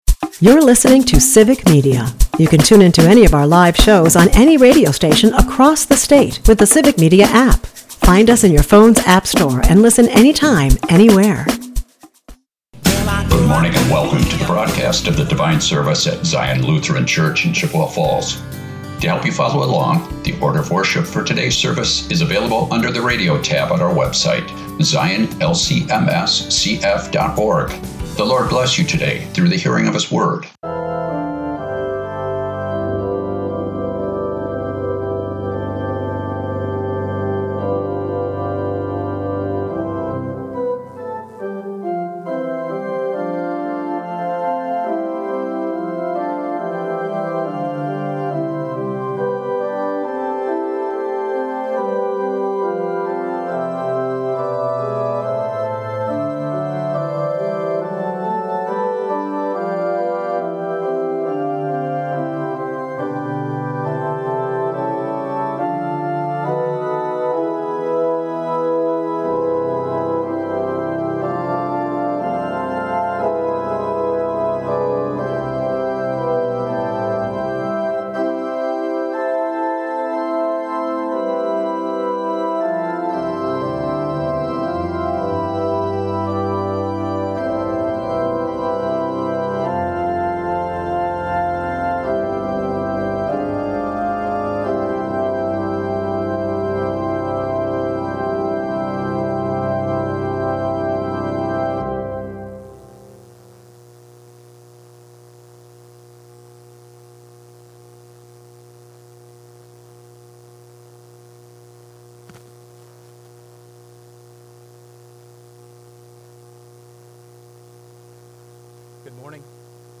Sunday Service - Civic Media